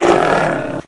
Divergent / mods / Soundscape Overhaul / gamedata / sounds / monsters / dog / hit_0.ogg